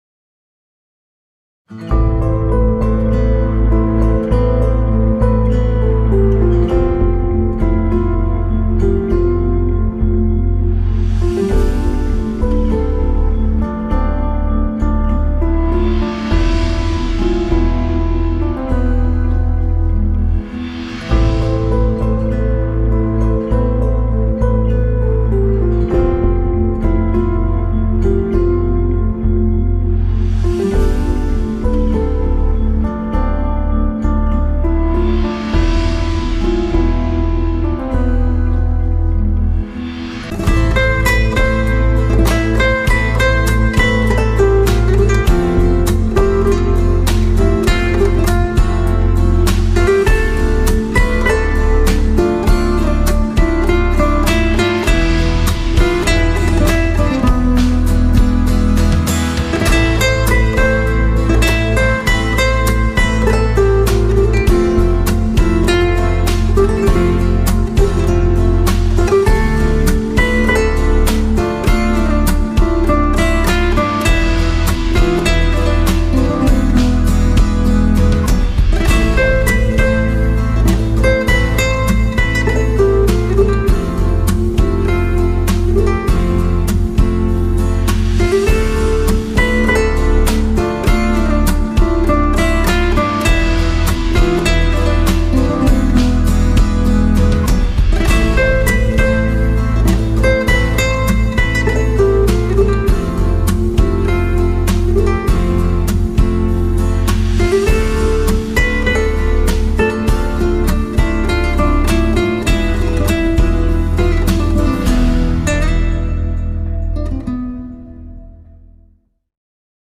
tema dizi müziği, mutlu huzurlu rahatlatıcı fon müziği.